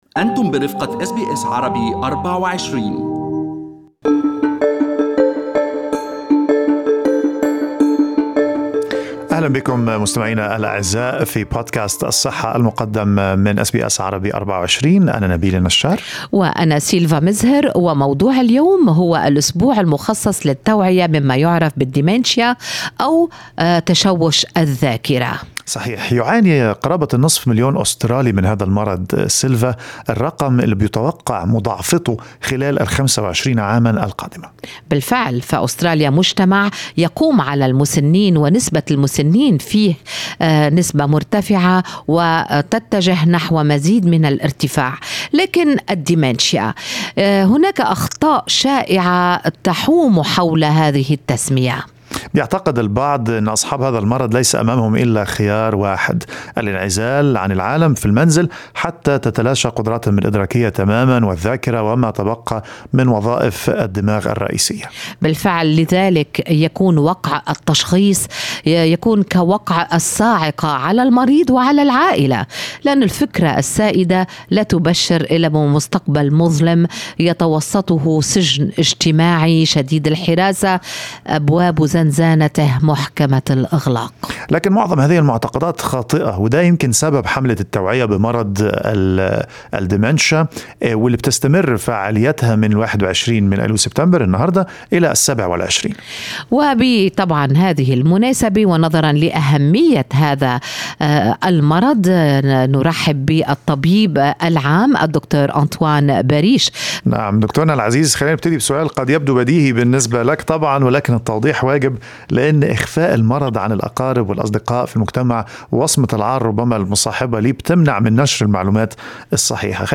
وفي لقاء خاص